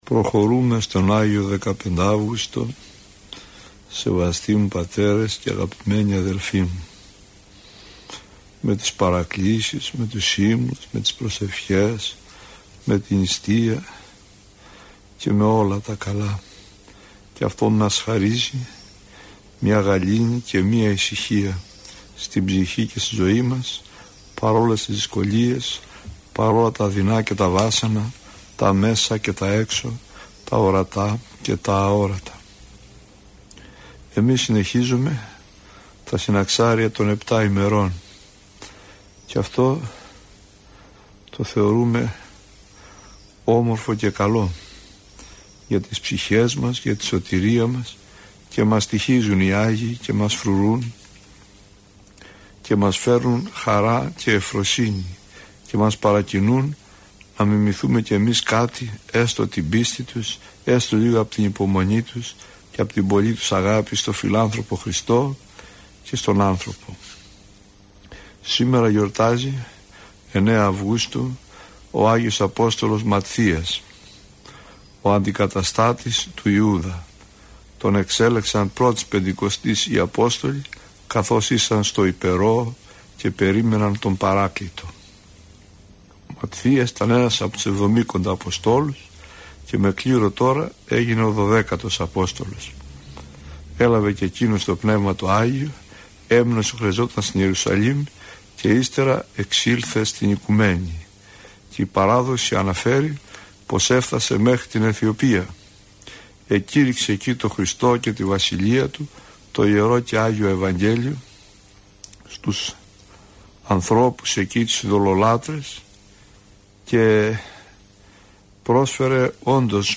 Άγιοι που εορτάζουν από 9 έως και 14 Αυγούστου – ηχογραφημένη ομιλία
Πρόκειται για εκπομπή που μεταδόθηκε από τον ραδιοσταθμό της Πειραϊκής Εκκλησίας.